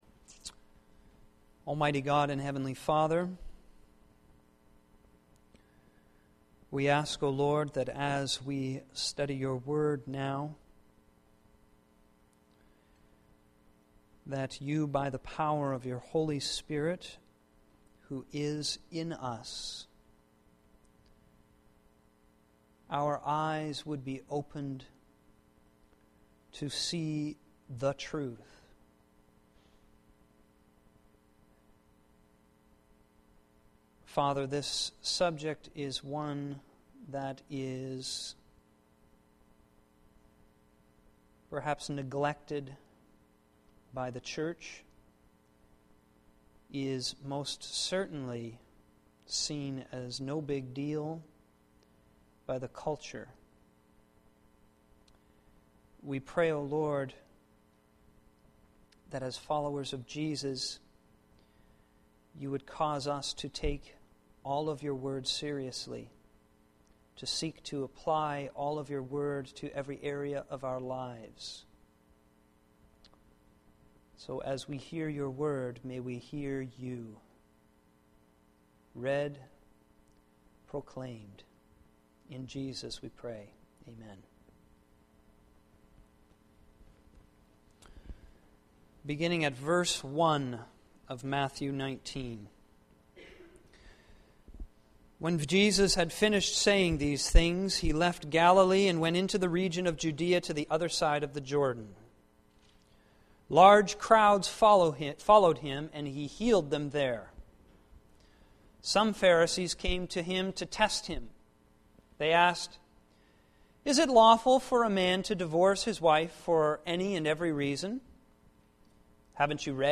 Sermon Library